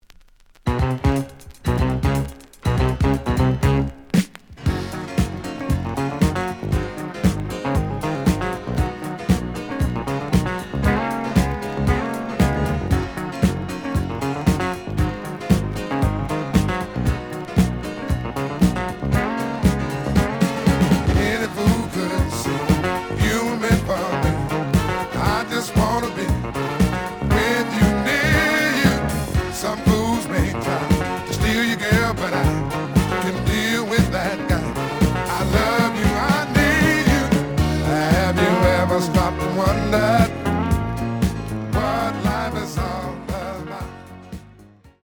(Mono)
The audio sample is recorded from the actual item.
●Genre: Disco